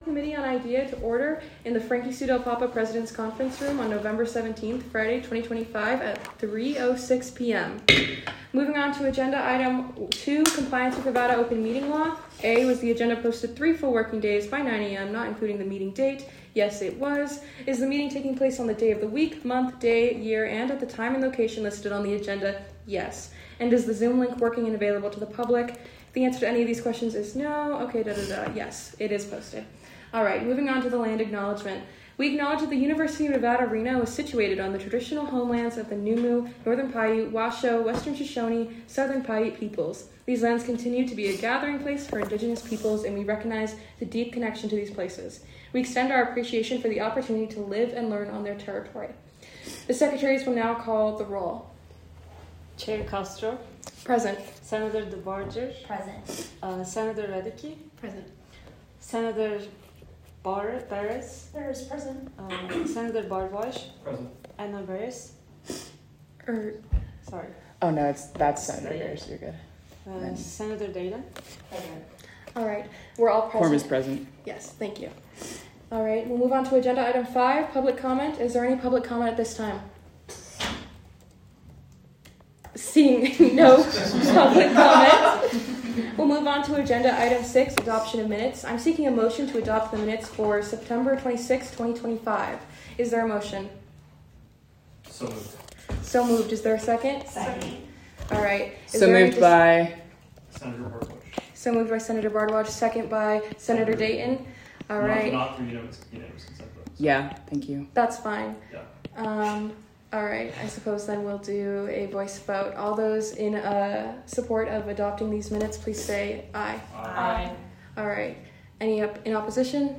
Location : Frankie Sue Del Papa Conference Room